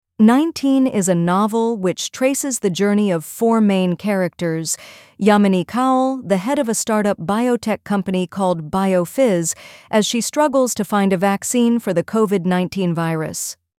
PLAY VOICE SAMPLE